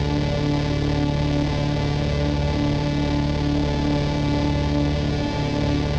Index of /musicradar/dystopian-drone-samples/Non Tempo Loops
DD_LoopDrone5-D.wav